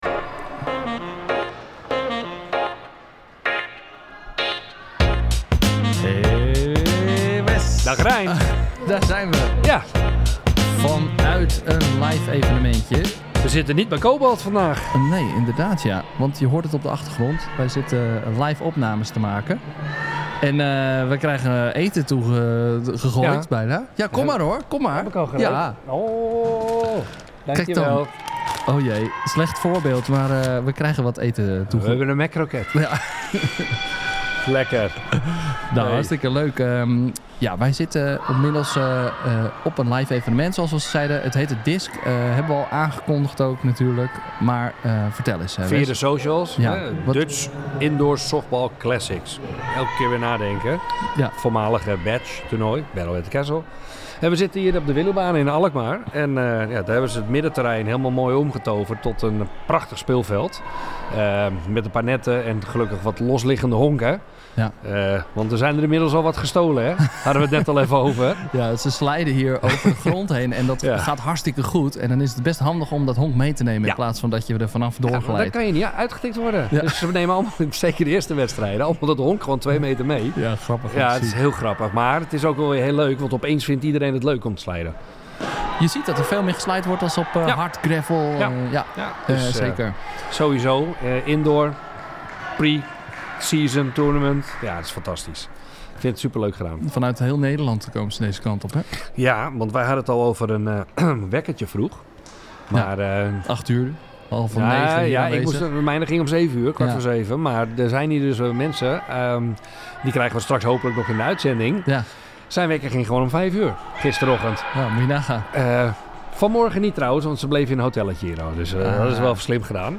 📍 00:00 – Intro: Een live opname vanuit Alkmaar!